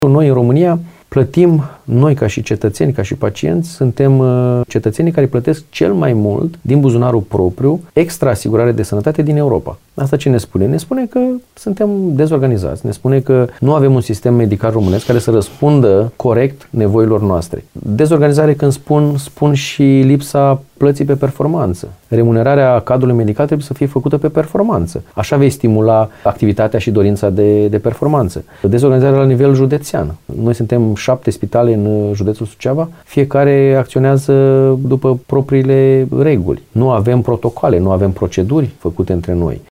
Într-un interviu acordat în exclusivitate postului nostru de radio, el a dat mai multe exemple.